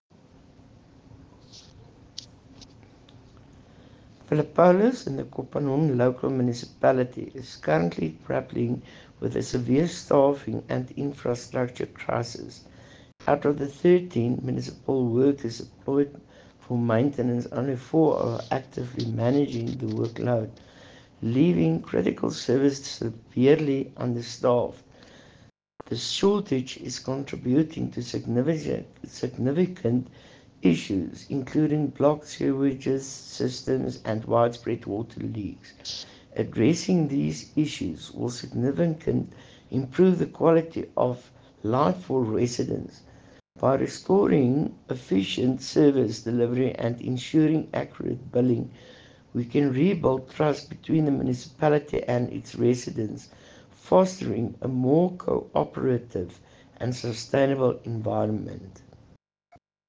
Afrikaans soundbites by Cllr Estelle Noordman and